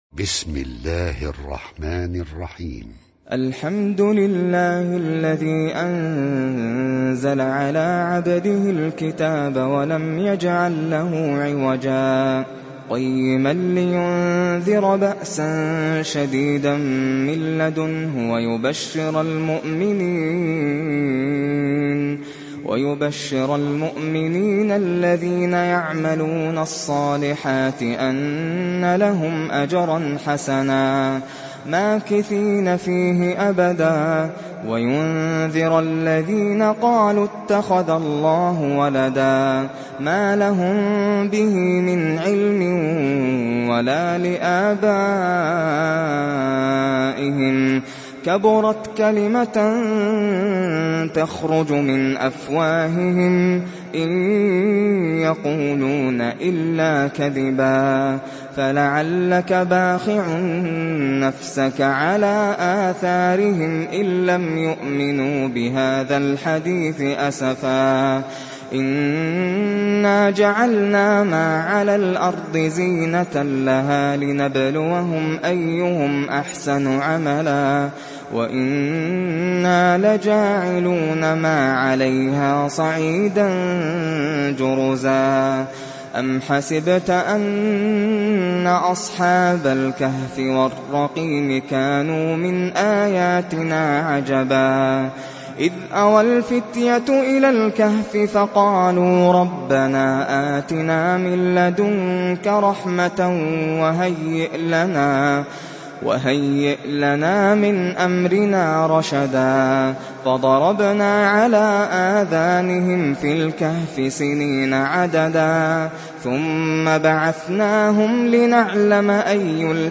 Kehf Suresi mp3 İndir Nasser Al Qatami (Riwayat Hafs)